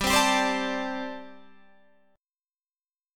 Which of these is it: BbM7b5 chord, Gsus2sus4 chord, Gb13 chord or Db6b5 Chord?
Gsus2sus4 chord